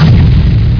Rock.wav